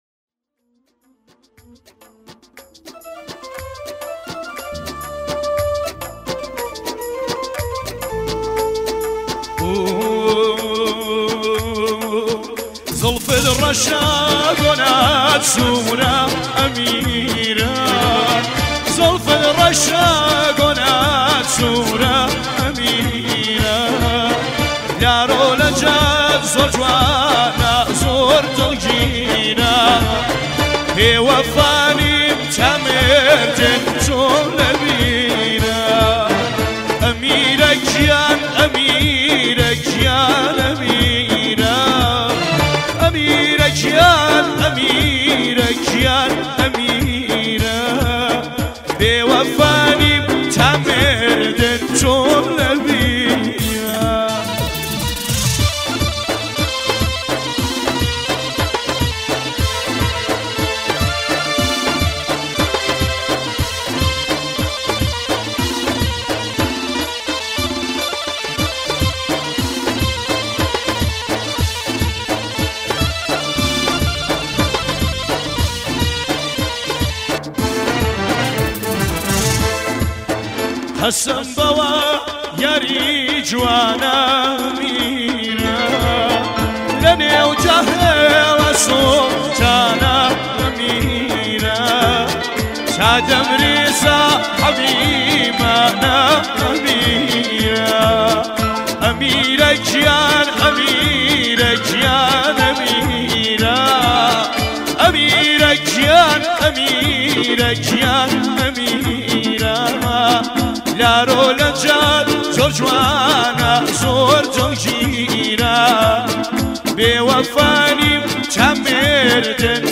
آهنگ گریان و شاد
آهنگ کوردی